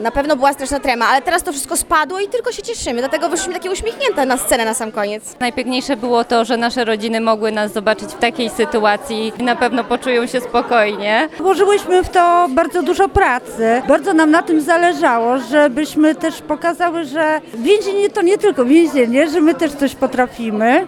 Emocji było sporo, o czym opowiadały już po zakończonym występie.
więźniarki